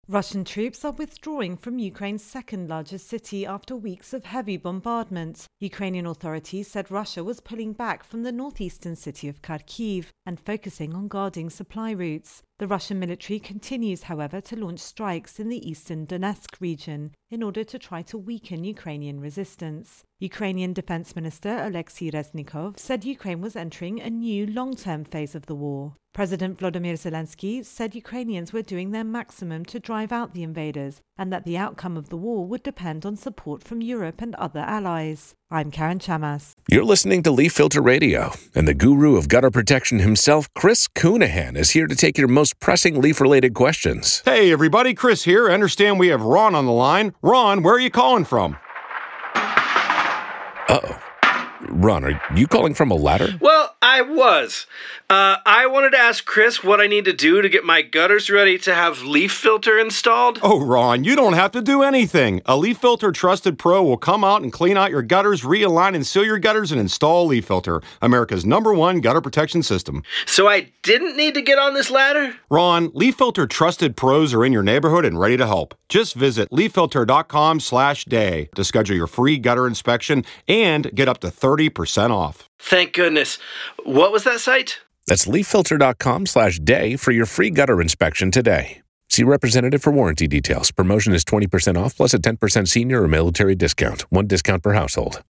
Intro and voicer on Russia Ukraine War.